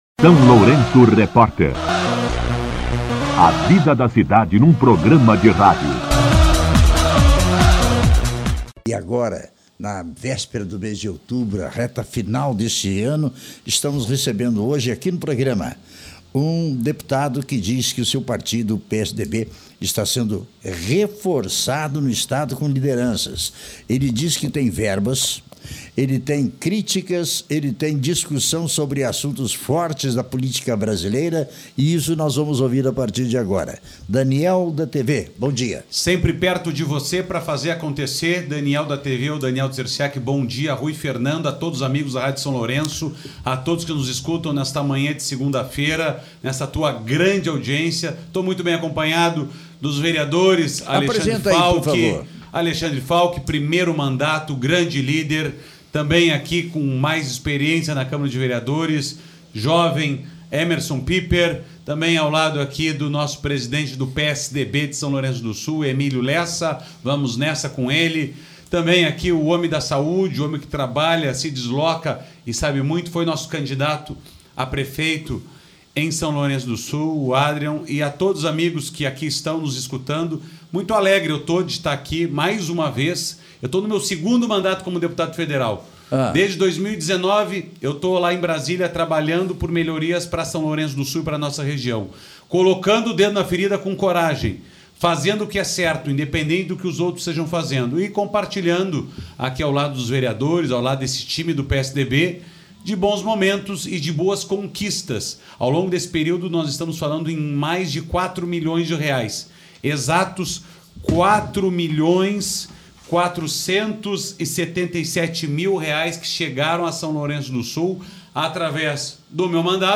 O deputado federal Daniel Trzeciak (PSDB) concedeu entrevista ao SLR RÁDIO na manhã desta segunda-feira (29) para falar sobre recursos destinados à comunidade de São Lourenço do Sul por meio de emendas parlamentares. Já foram contempladas áreas como saúde, maquinário agrícola, Apae, segurança pública, entre outras.